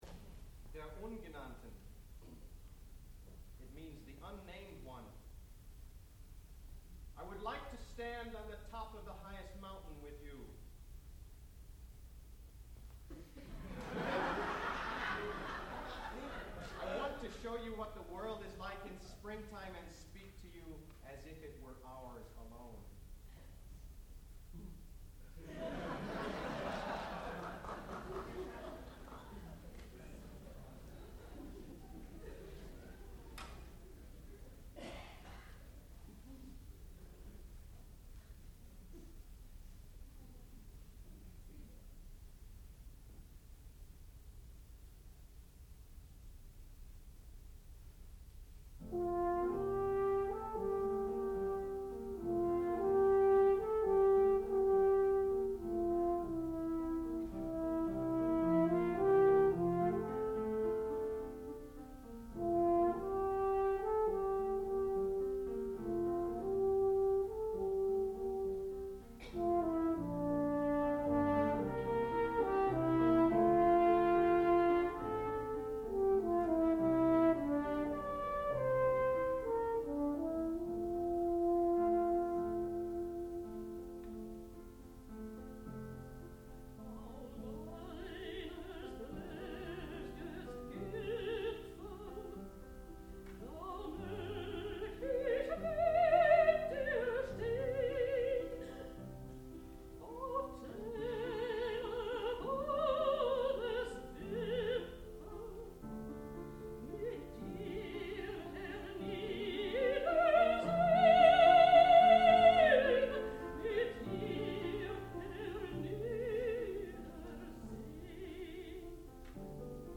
sound recording-musical
classical music
piano
mezzo-soprano
horn